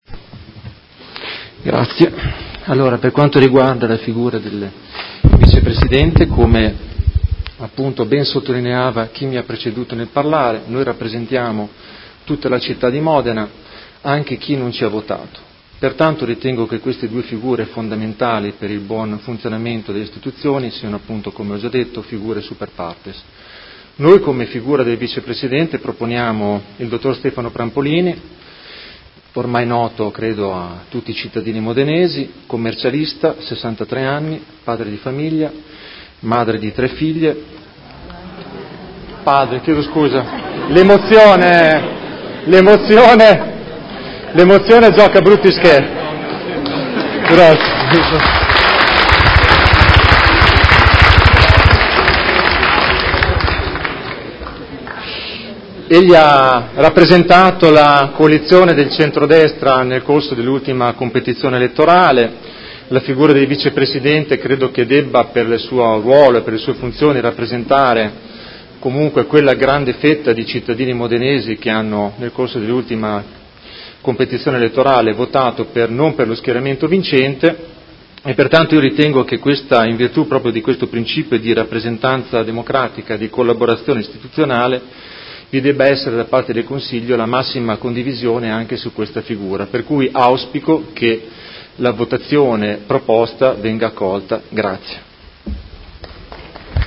Seduta del 13/06/2019 Elezione Presidente e Vicepresidente. Propone Stefano Prampolini come Vicepresidente.